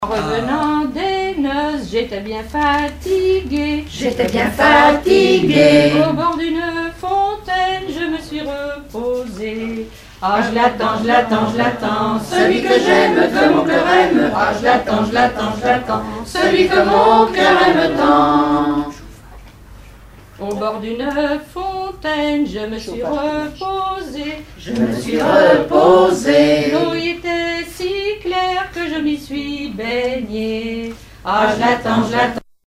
Mémoires et Patrimoines vivants - RADdO est une base de données d'archives iconographiques et sonores.
Chantonnay
Genre laisse
Pièce musicale inédite